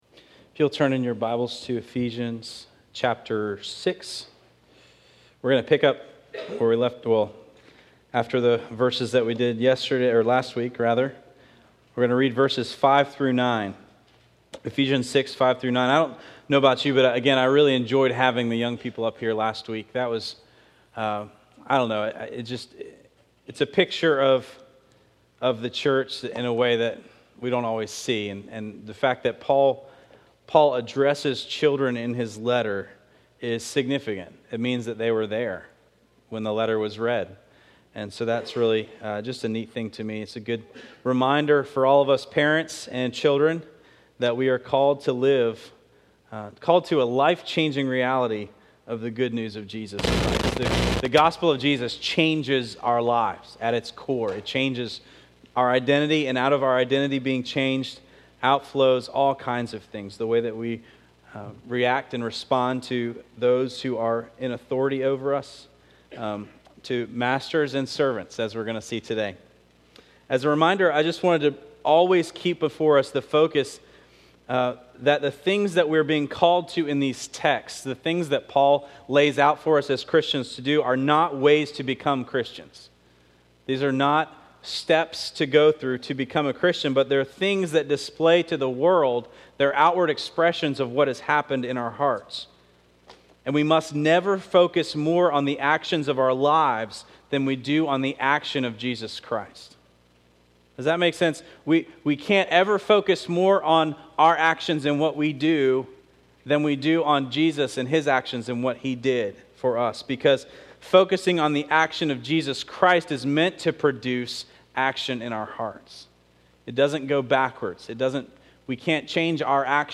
This week’s sermon focuses on our workplaces, and how the way we respond to our bosses or employees is one of the primary ways we share the Gospel with them.